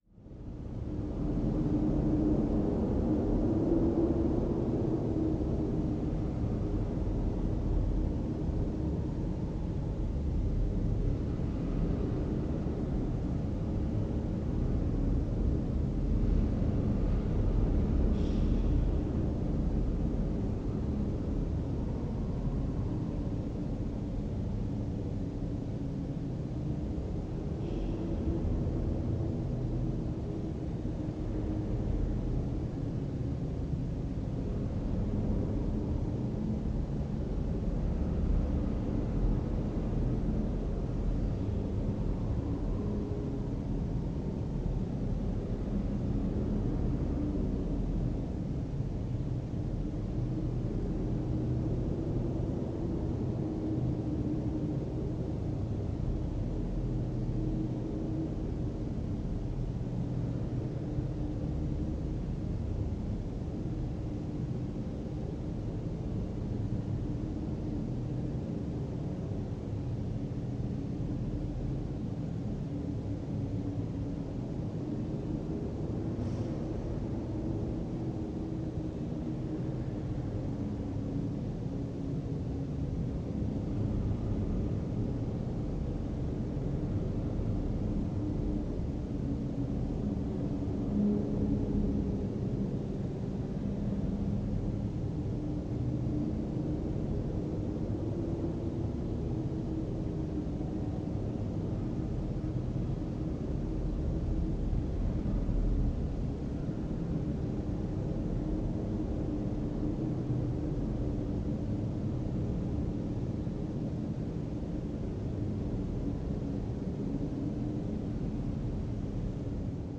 Здесь собраны реалистичные аудиоэффекты: скрип половиц, завывание ветра в пустых комнатах, отдаленные голоса и другие жуткие детали.
Жуткий звук заброшенного дома